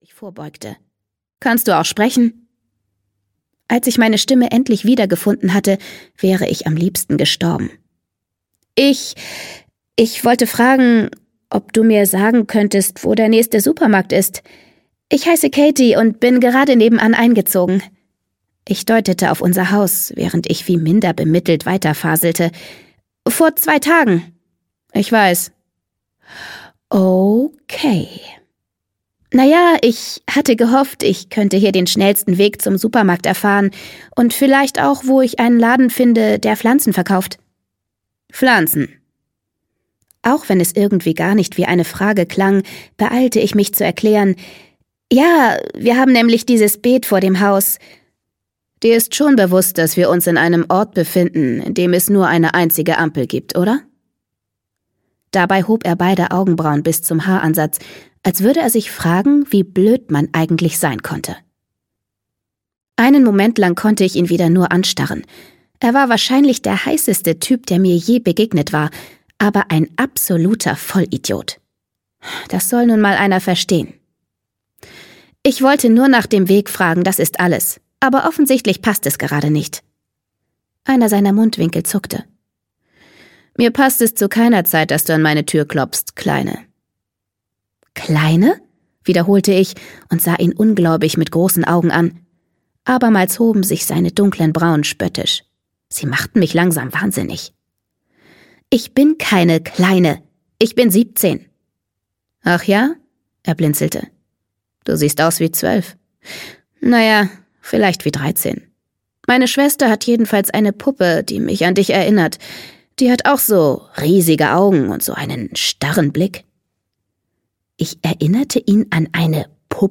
Obsidian 1: Obsidian - Jennifer L. Armentrout - Hörbuch